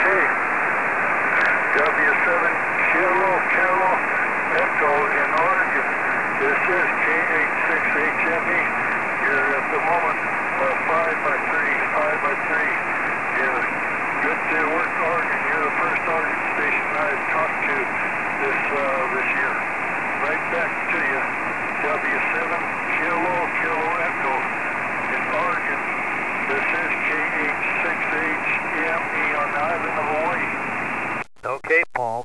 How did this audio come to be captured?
Station consists of: FT-847 with 100 Watt Mirage amps for 2 meters and 70 cm.